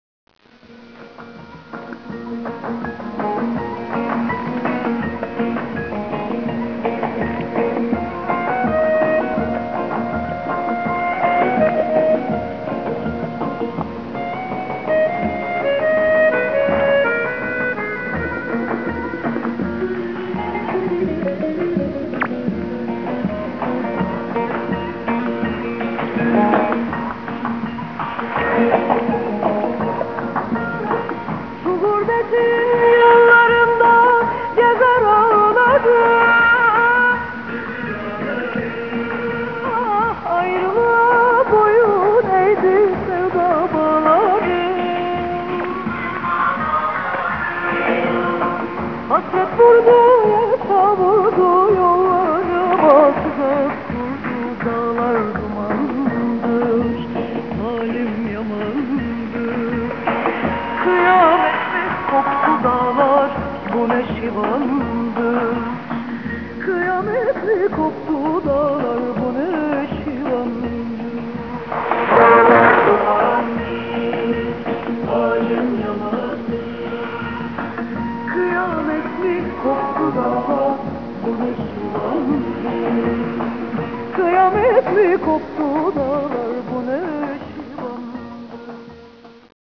音声ファイル（ソニーSW1000Tと八木アンテナ使用、1997年4月4日録音）
wav568k　民族音楽